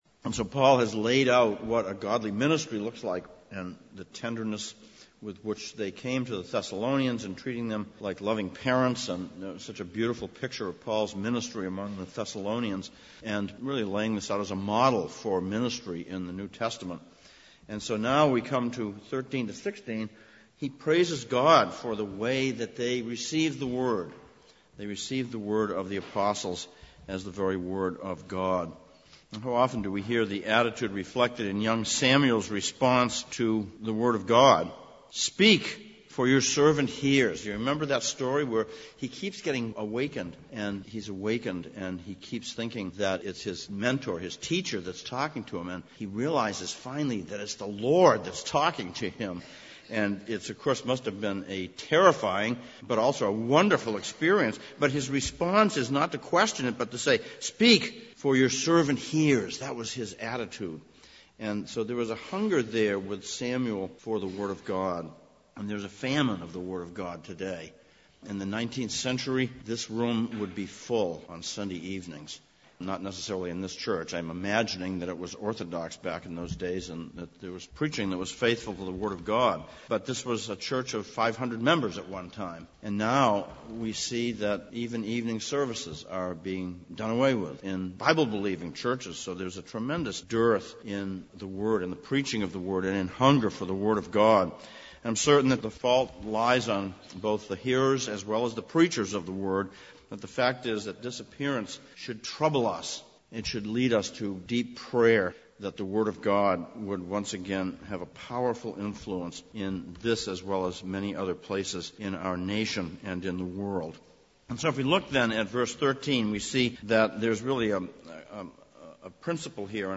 Sermon text: 1 Thessalonians 2:13-16
Service Type: Sunday Evening